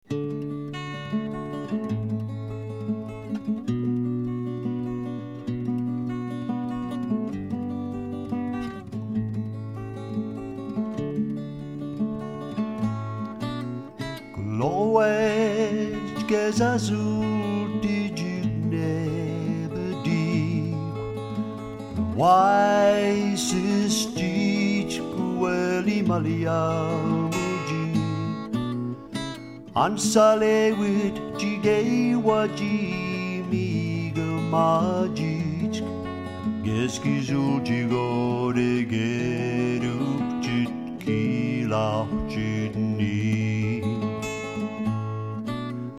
Here’s part of a song in a mystery language. Can you identify the language?